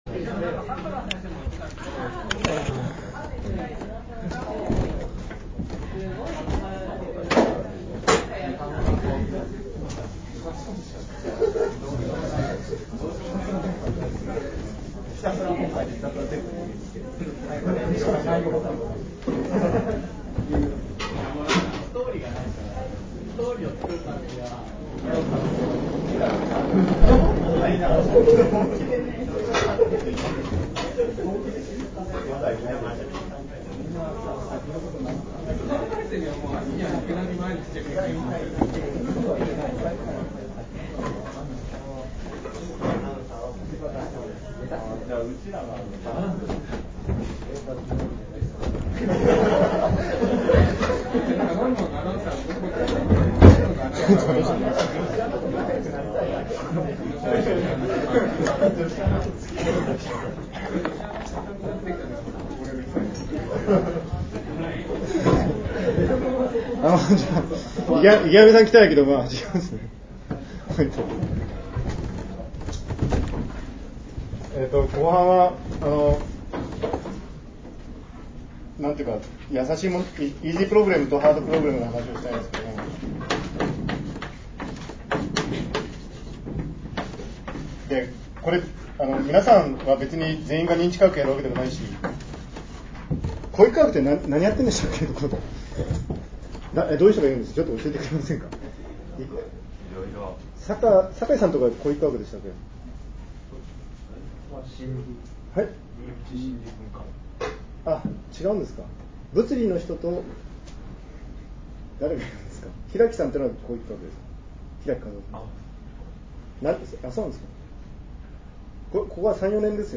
東京大学総合文化研究科特別講議 「心脳問題の基礎」 ２／２...